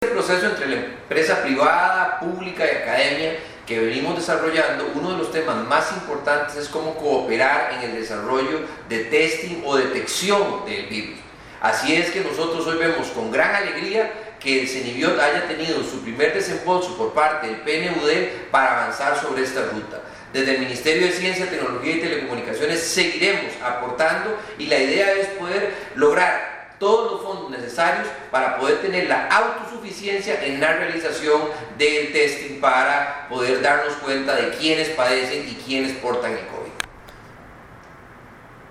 Audio del ministro Luis Adrián Salazar sobre desarrollo de Costa Rica de sus propias pruebas para detección de COVID-19